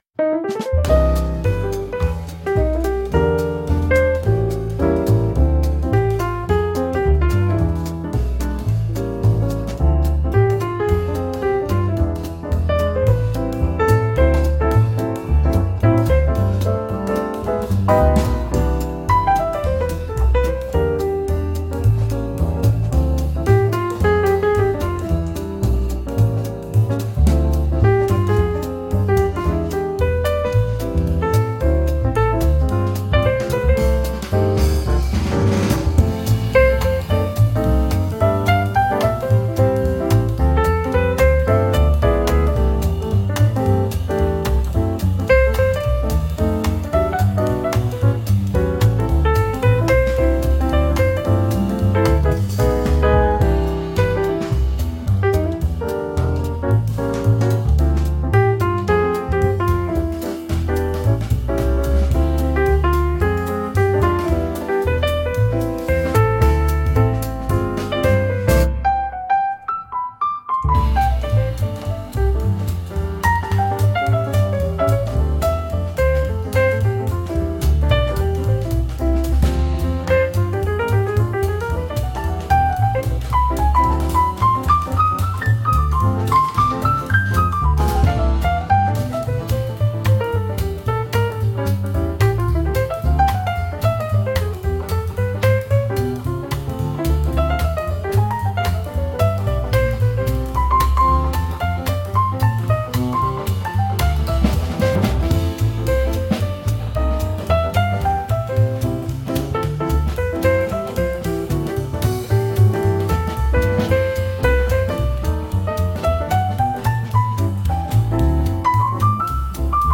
ピアノトリオのジャズ
古典的なピアノ・トリオであるピアノ・ベース・ドラムからなるジャズを作ってみましょう。
Jazz Trio
Instrumental only, No vocals, Relaxed and expressive jazz piano trio with acoustic piano, upright bass, and soft drums using brushes, Moderate swing tempo around 110 BPM, Warm and intimate atmosphere, subtle harmonies and conversational interplay between instruments
スタンダードなピアノトリオの楽曲ができあがりました。
全編通しても破綻している部分がなく、完成度の高い仕上がりです。